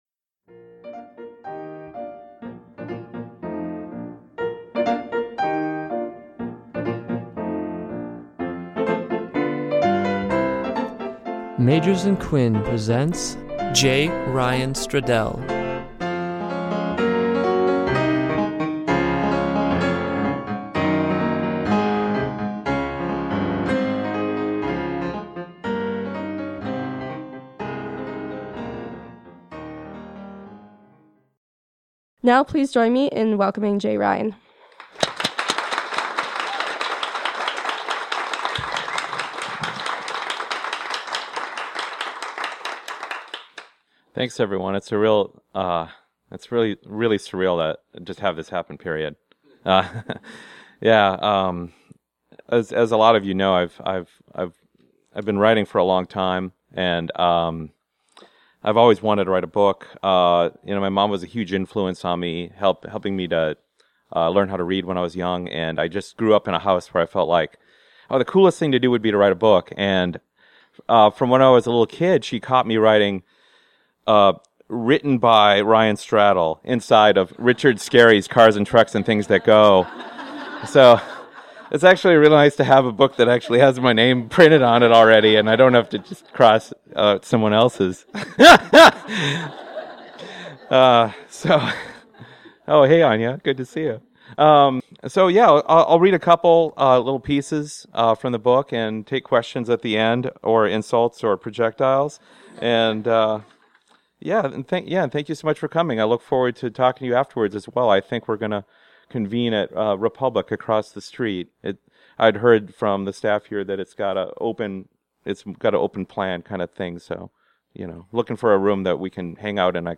Relive our reading with J. Ryan Stradal, author of the hit novel Kitchens of the Great Midwest. Recorded at Magers and Quinn Booksellers on August 2, 2015.